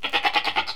goat.wav